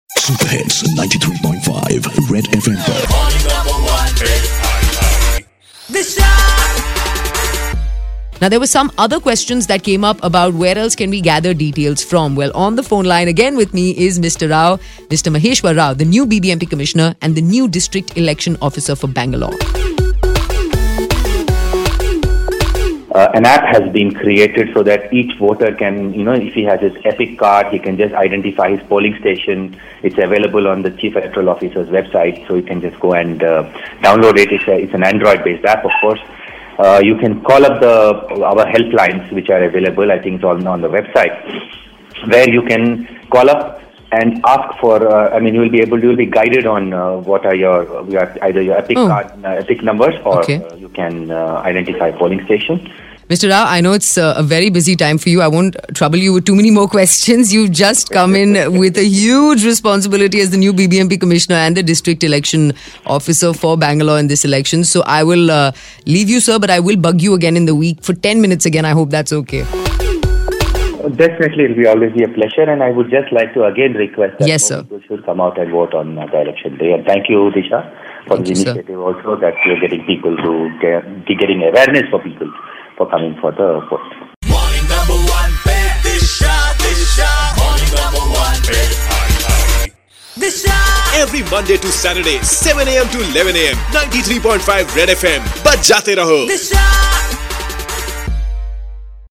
BBMP Commissioner explaining the 'Chunavana App'